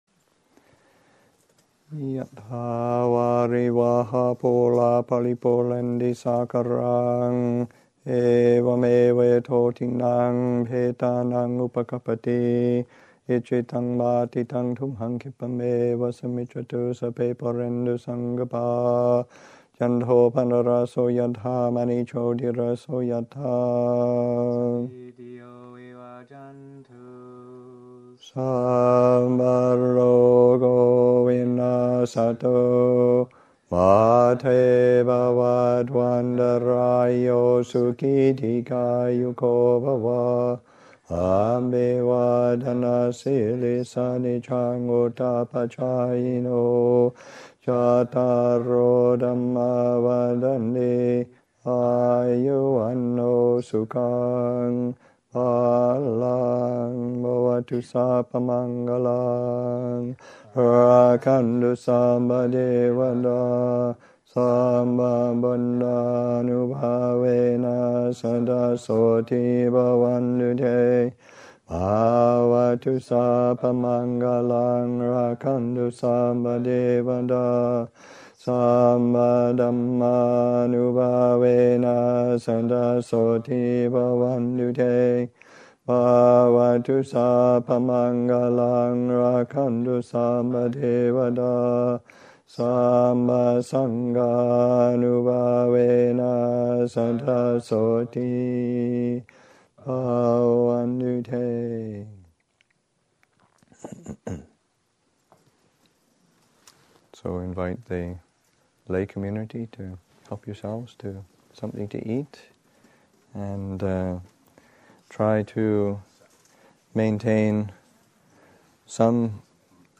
Anumodana chanting: “Yathā vāri-vahāpūrā...” and “Bhavatu sabba-maṅgalaṃ...”